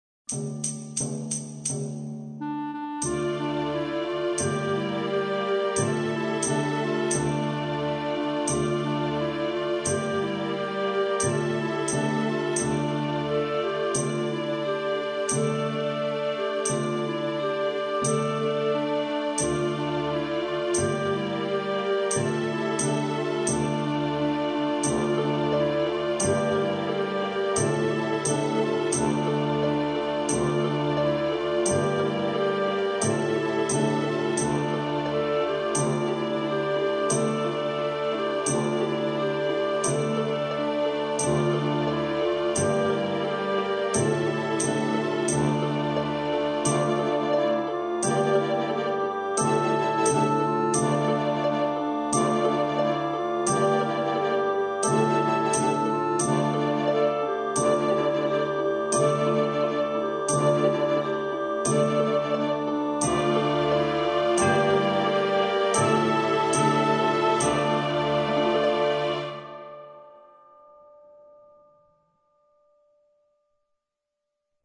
Attiva la base e segui lo spartito.